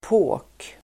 Ladda ner uttalet
påk substantiv (vardagligt " ben"), thick stick [informal " leg"] Uttal: [på:k] Böjningar: påken, påkar Synonymer: klubba, slagträ Definition: kraftig käpp Exempel: rör på påkarna!